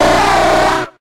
Grito de Seel.ogg
Grito_de_Seel.ogg.mp3